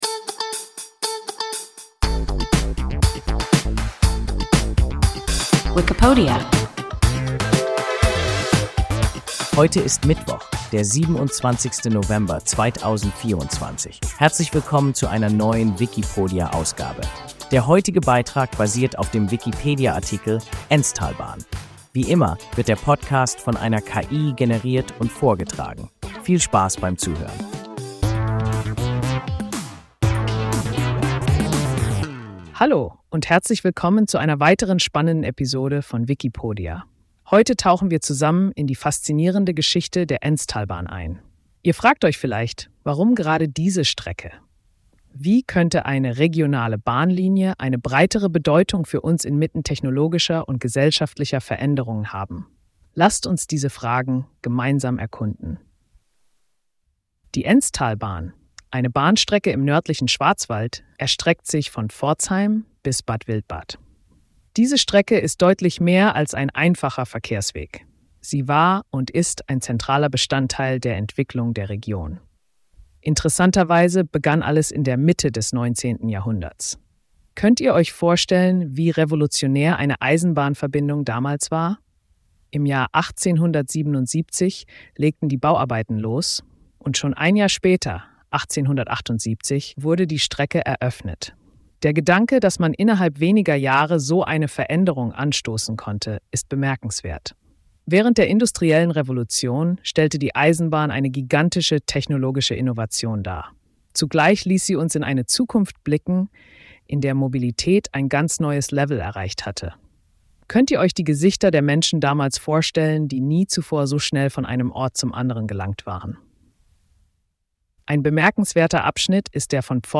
Enztalbahn – WIKIPODIA – ein KI Podcast